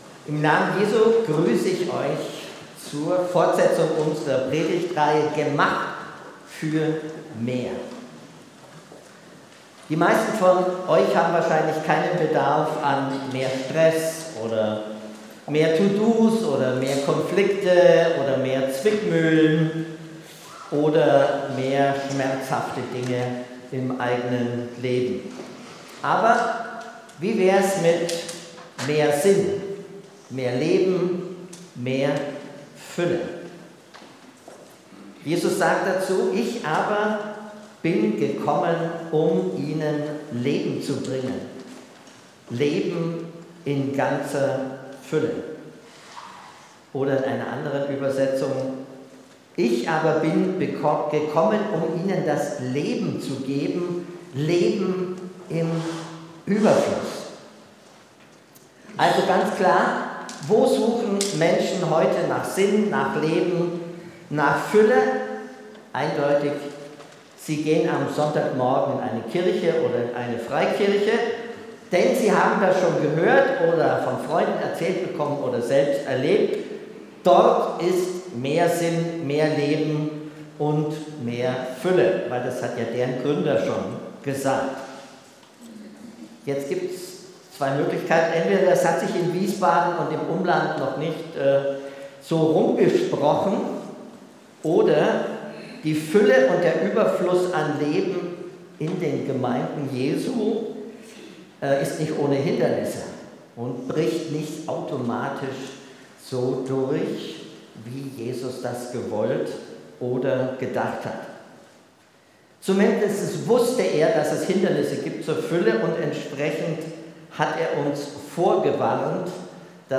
Gottesdienst mit Abendmahl